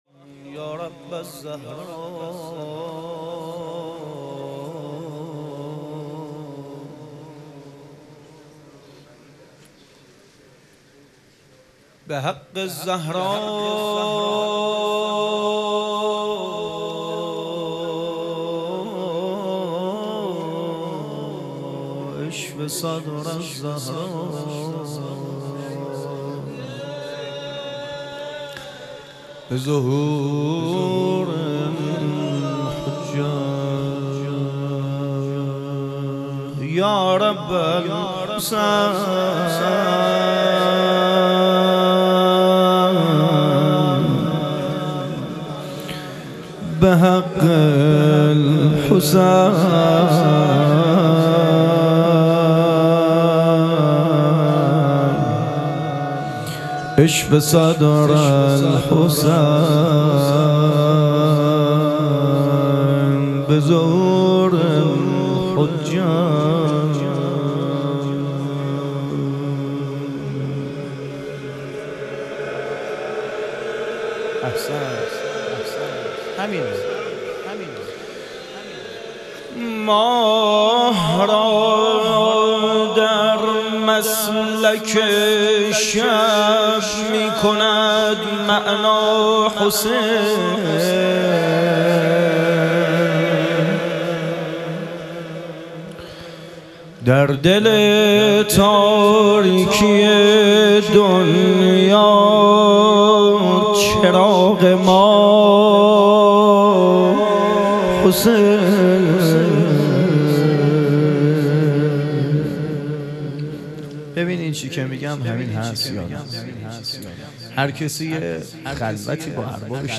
شهادت حضرت مسلم علیه السلام - روضه